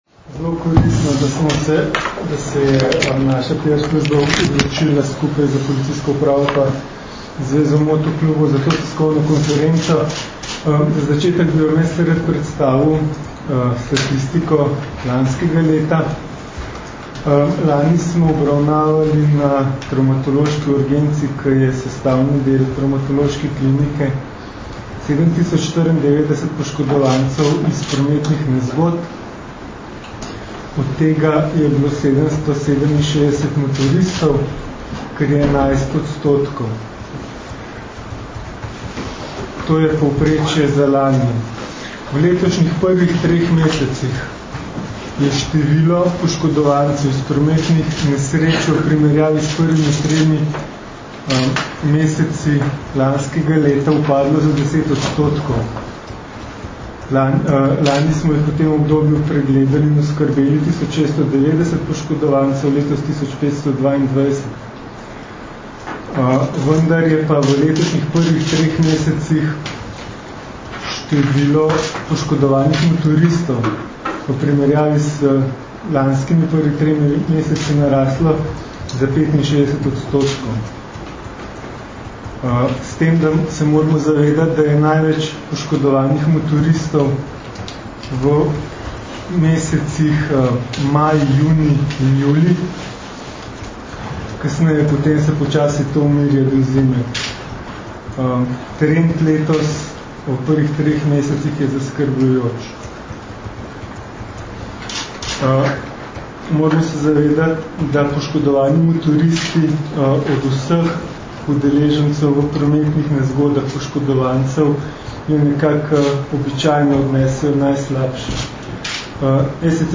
Zdravniki, policisti in motoristi skupaj za varno motoristično sezono - informacija z novinarske konference